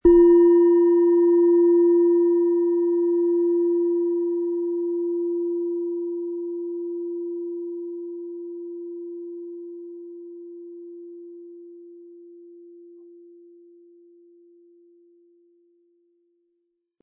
Antike Klangschalen - gesammelte Unikate
Die Oberfläche zeigt sanfte Spuren der Zeit - doch ihr Klang bleibt rein und klar.
Diese Schale, die im tiefgreifenden Ton von Chiron schwingt, bietet Unterstützung bei der Versöhnung mit sich selbst.
Der Klang dieser Schale ist eine Mischung aus tiefgreifender Intensität und langanhaltender Präsenz. Er entfaltet eine schwingungsvolle Dynamik, die dazu einlädt, tief in das Klanggeschehen einzutauchen.
Um den Originalton der Schale anzuhören, gehen Sie bitte zu unserer Klangaufnahme unter dem Produktbild.